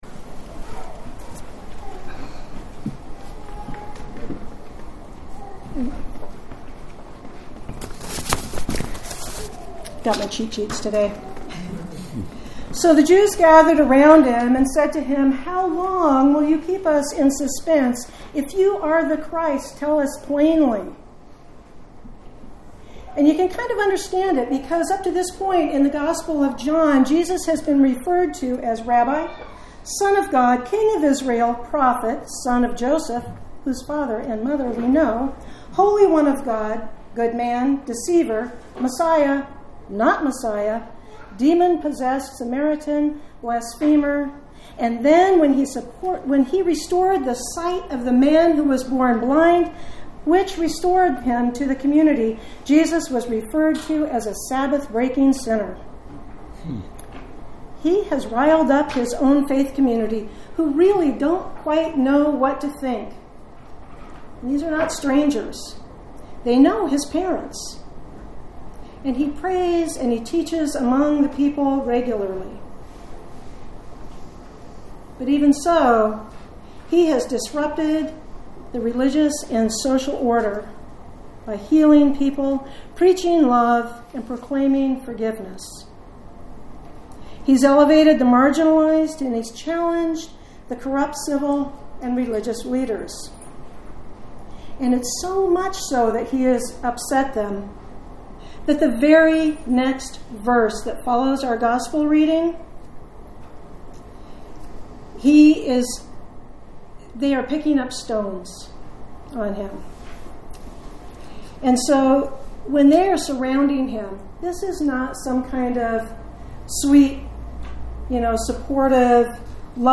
Sermons | Lake Chelan Lutheran Church